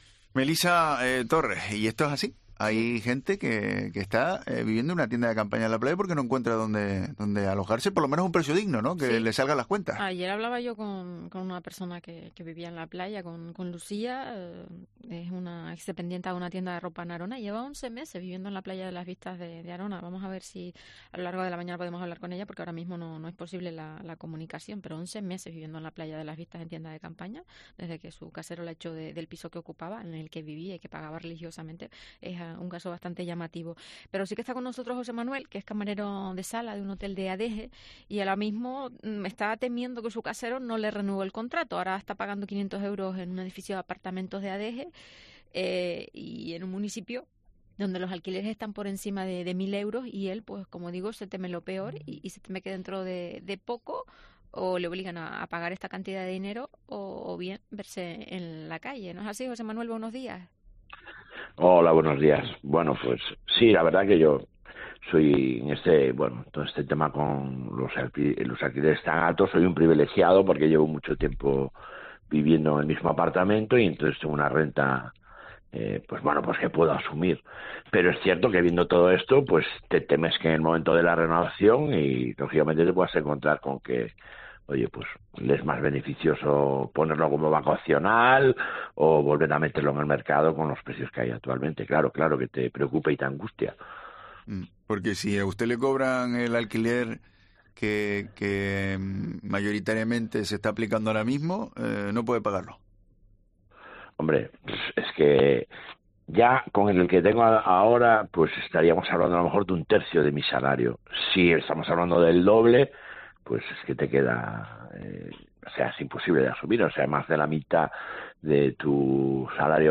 En los micrófonos de Herrera en COPE Canarias hemos analizado este viernes los problemas a los que se enfrentan los trabajadores del sector turístico a la hora de alquilar un inmueble en municipios como Adeje, Arona o Guia de Isora.